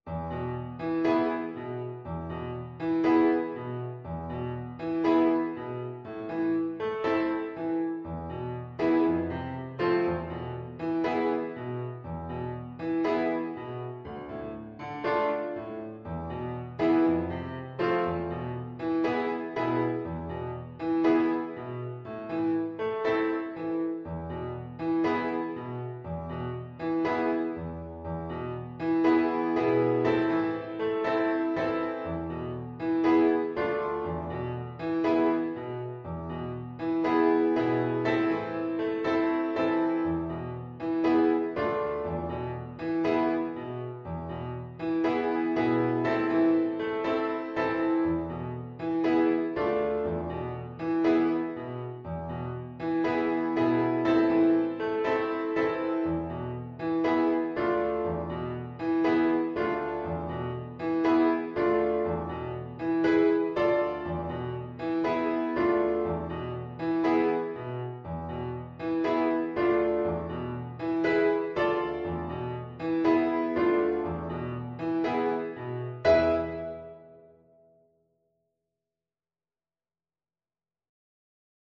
Play (or use space bar on your keyboard) Pause Music Playalong - Piano Accompaniment Playalong Band Accompaniment not yet available reset tempo print settings full screen
A minor (Sounding Pitch) E minor (French Horn in F) (View more A minor Music for French Horn )
Allegro moderato =120 (View more music marked Allegro)
4/4 (View more 4/4 Music)